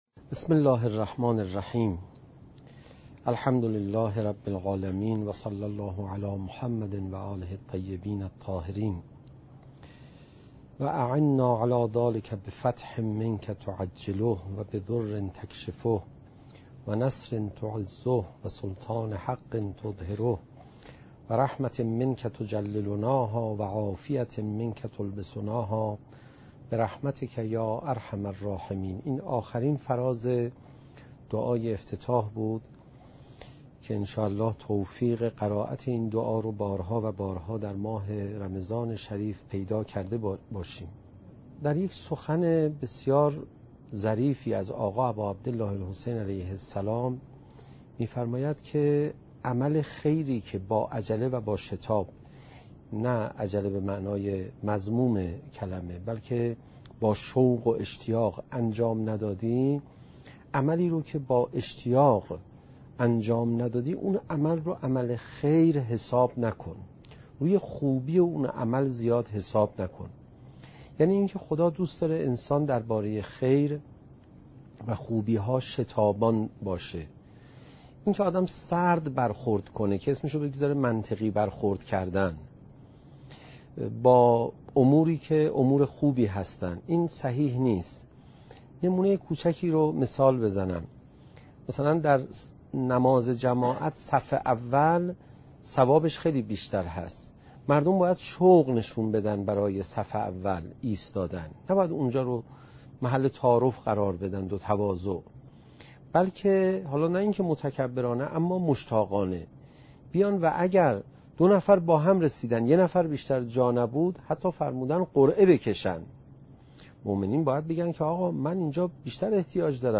قاری : حجت الاسلام عليرضا پناهیان